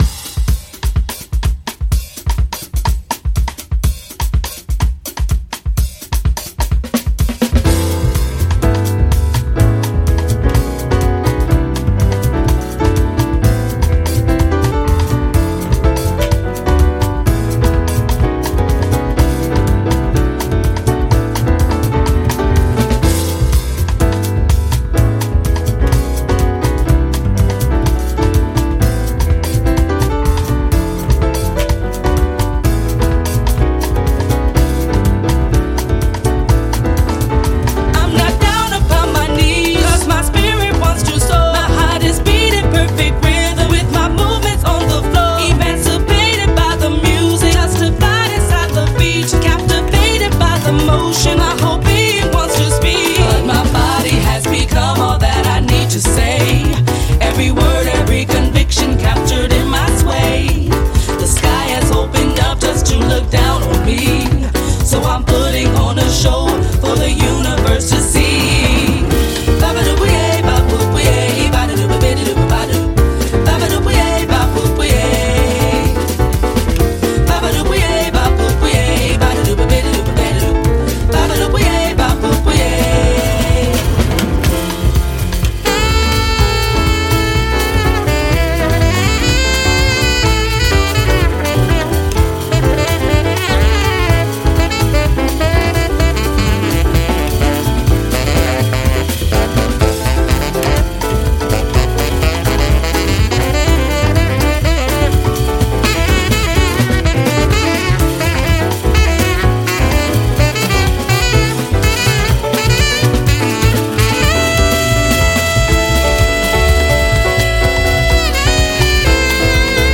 Disco, Disco edits